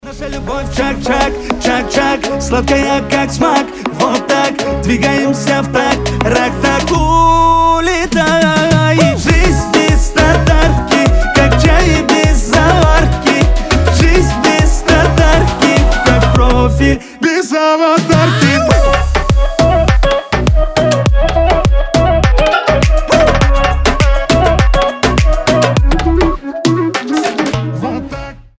• Качество: 320, Stereo
восточные
татарские